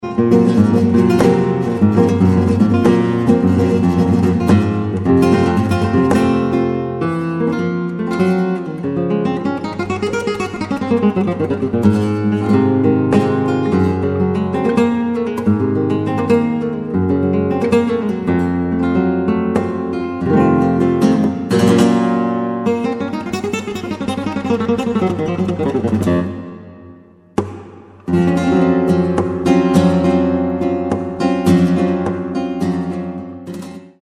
composer, lute & oud player from Japan
Flamenco , Guitar